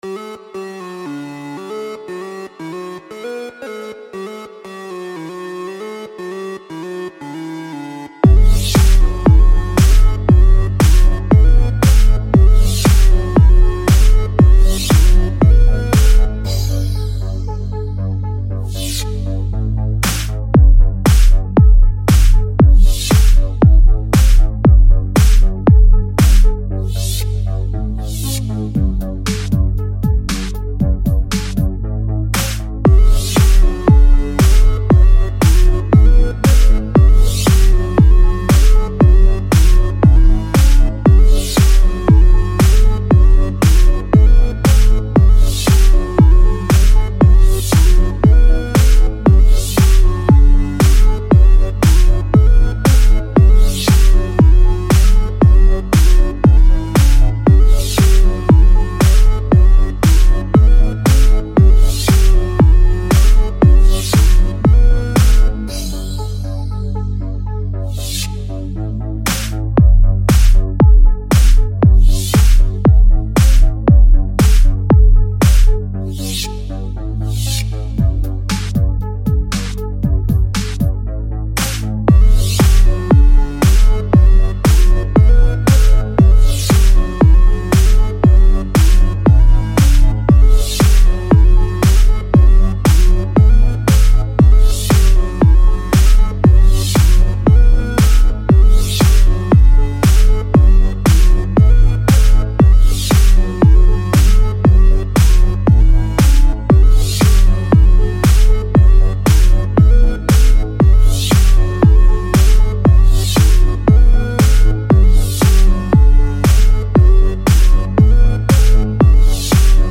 instrumental rithm and bass